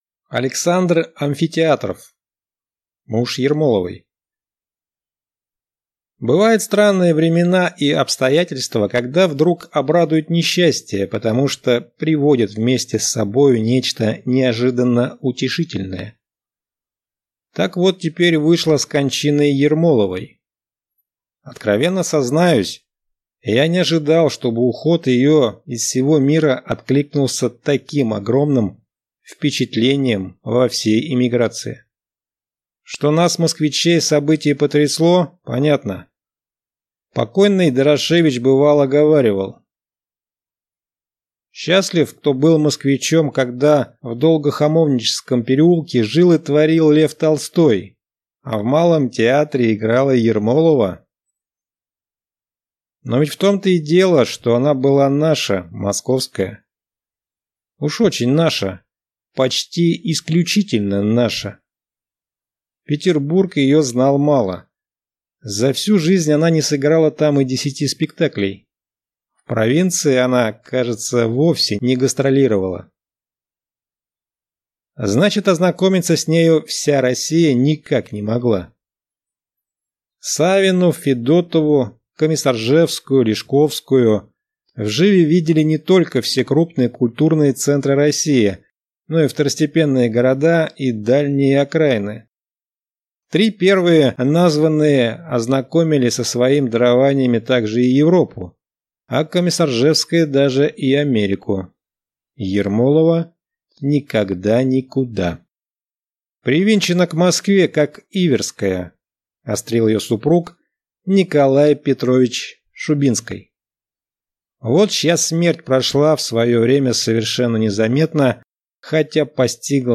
Аудиокнига Муж Ермоловой | Библиотека аудиокниг